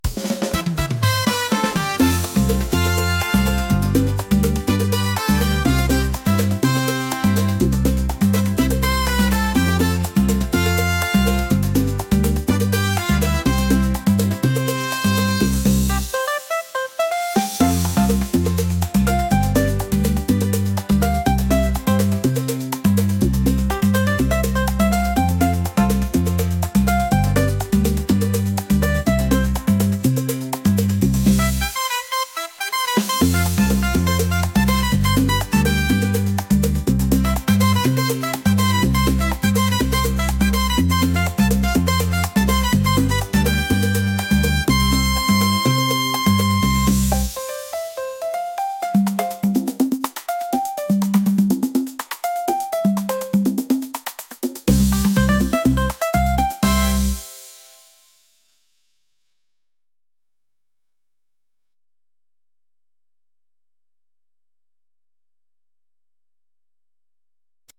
energetic | upbeat | latin